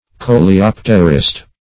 coleopterist - definition of coleopterist - synonyms, pronunciation, spelling from Free Dictionary
Coleopterist \Co`le*op"ter*ist\